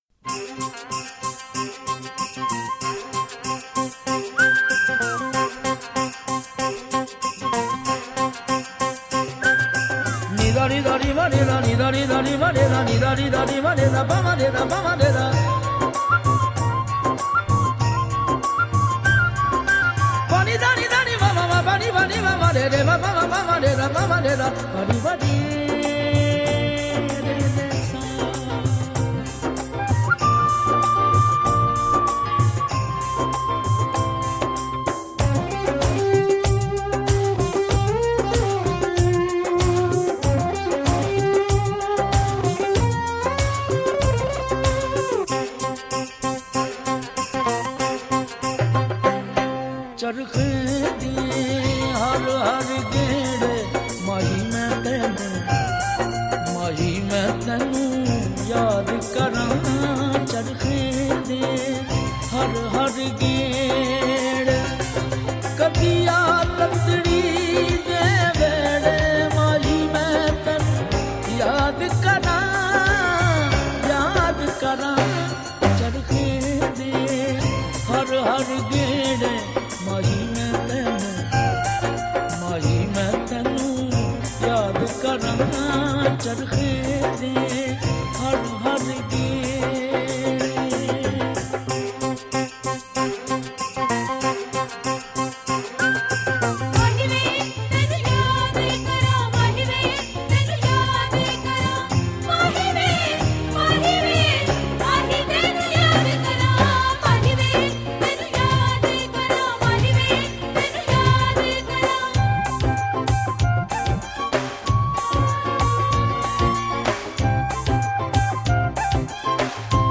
Sufi Collection